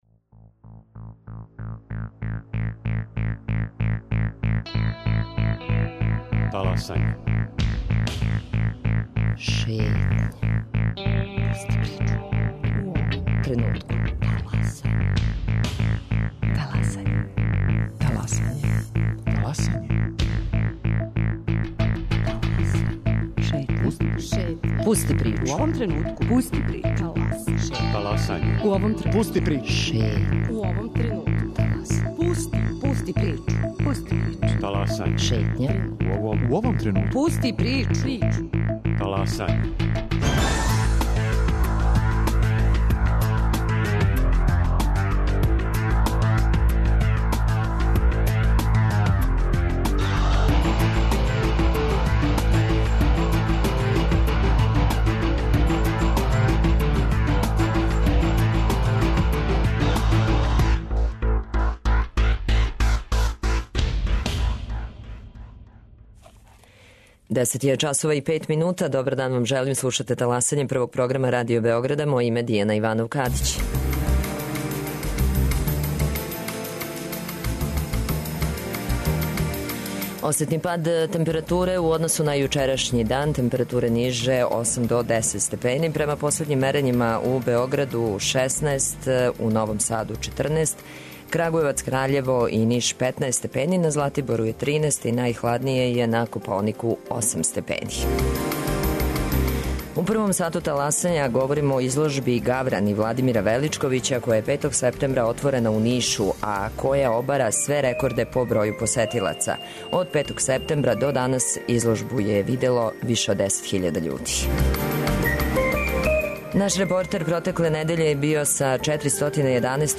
Међу њима су били и наши репортери који су забележили најзањимљивије тренутке.